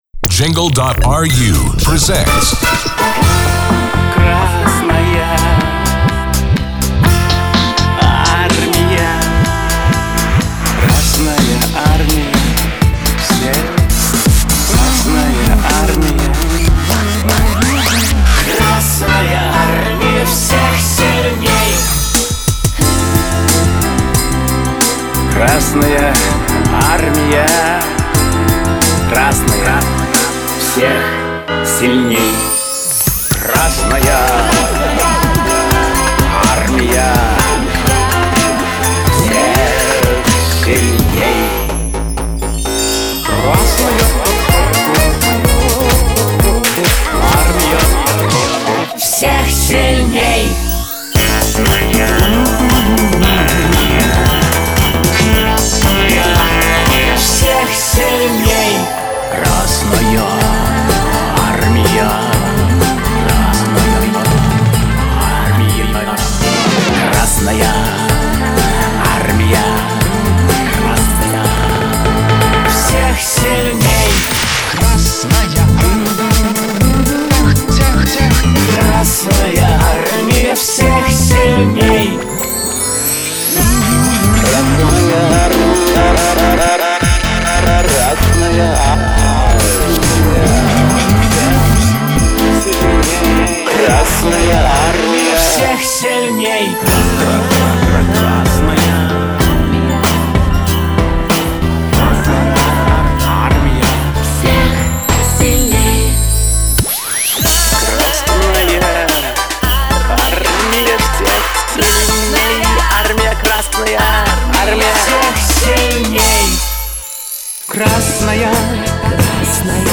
Оформление эфира радиостанции, продакшн